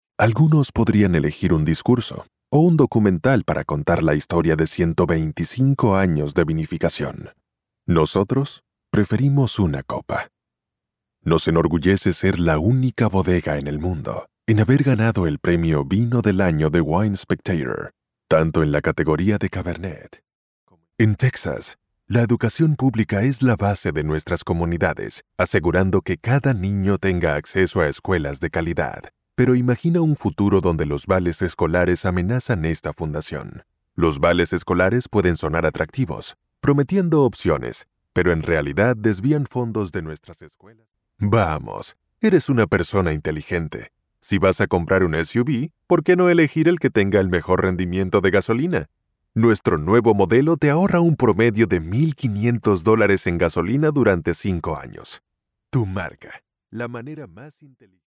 Male
Confident, Corporate, Deep, Engaging, Upbeat, Versatile
Studio_Dry.mp3
Microphone: Neumann TLM 103, Shure SM7dB
Acoustically treated studio.